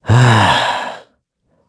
Clause_ice-Vox_Sigh_kr.wav